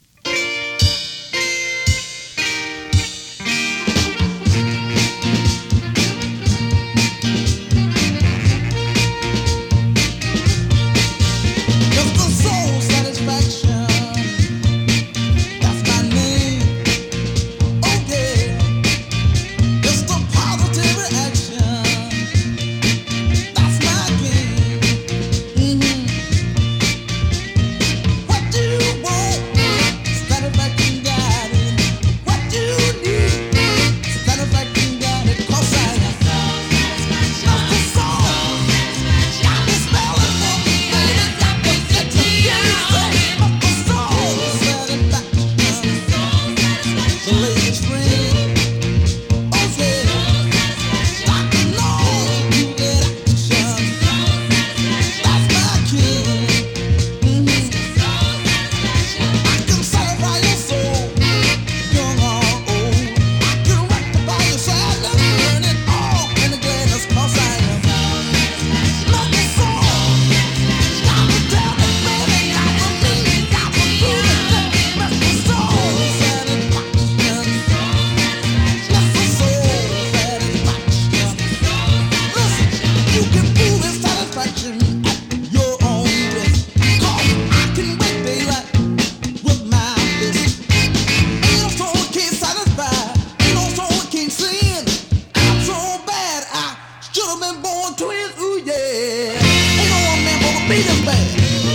US 45 ORIGINAL 7inch シングル NORTHERN SOUL 試聴
Great Detroit northern soul.
試聴 (実際の出品物からの録音です)